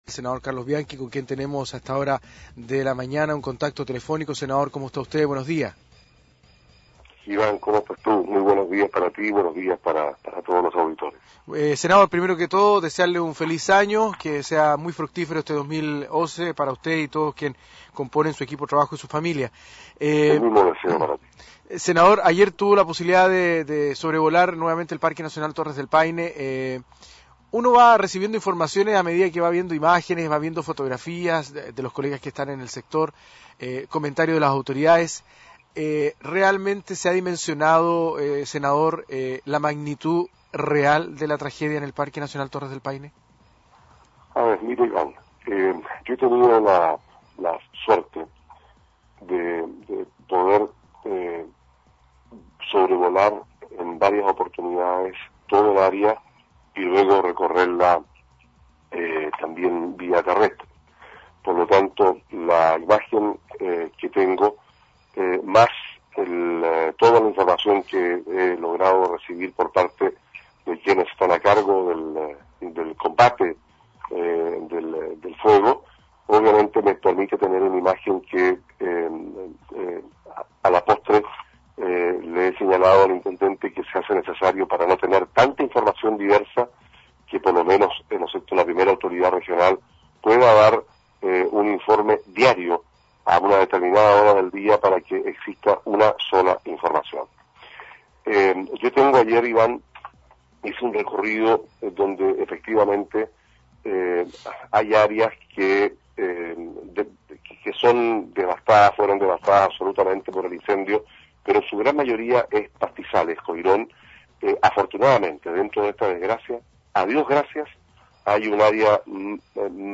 Entrevistas de Pingüino Radio - Diario El Pingüino - Punta Arenas, Chile
Carlos Bianchi, senador